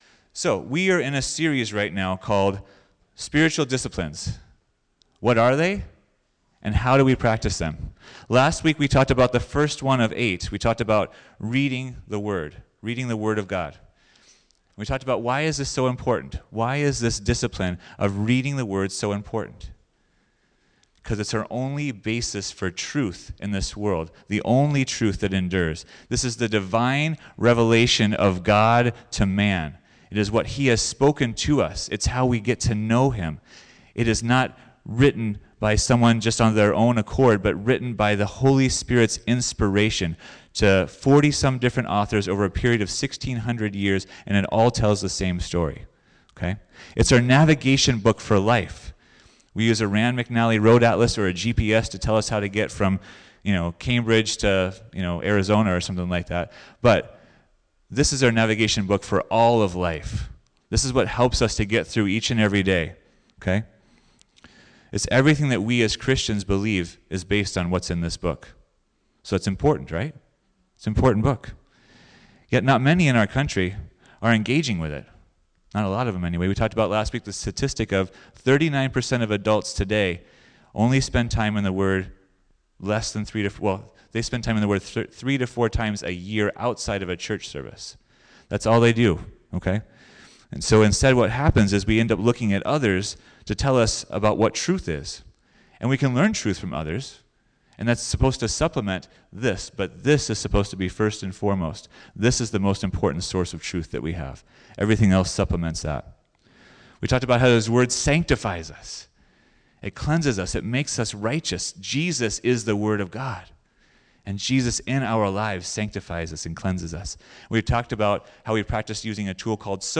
Note: We had many people share testimonies at the end, so only the first one is included at the end of the message.